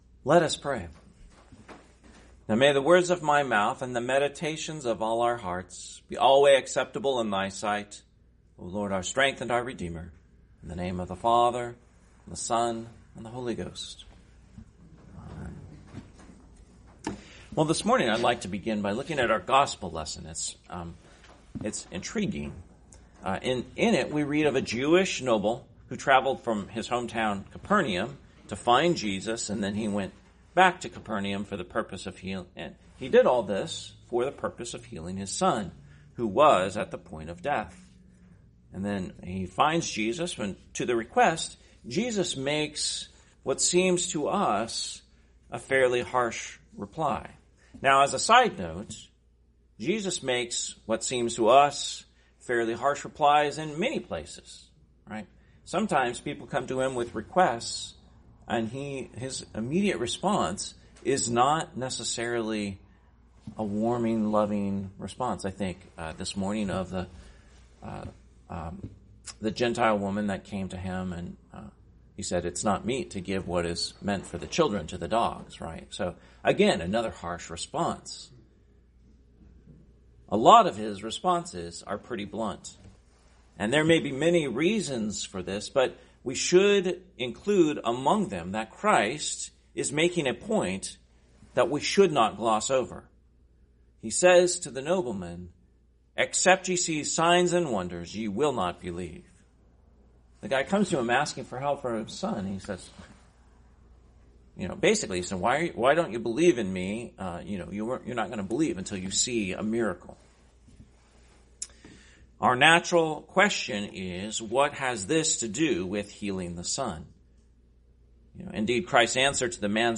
Sermon, 21st Sunday After Trinity, 2024